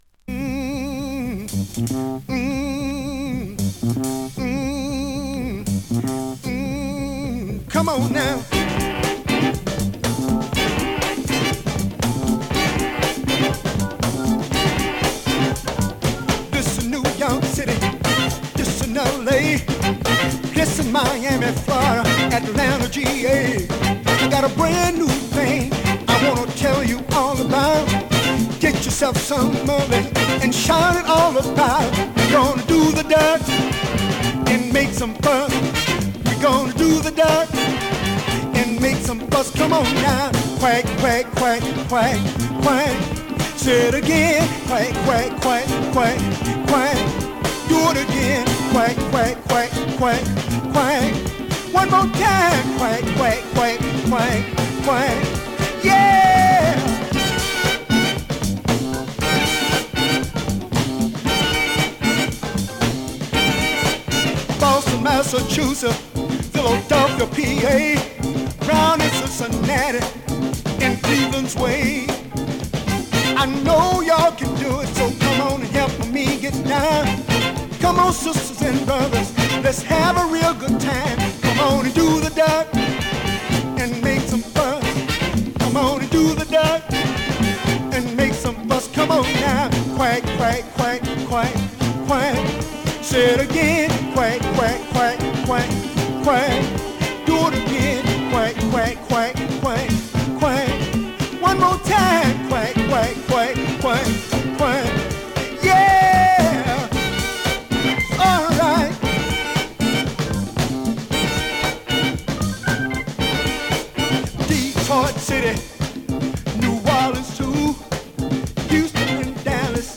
現物の試聴（両面すべて録音時間６分５５秒）できます。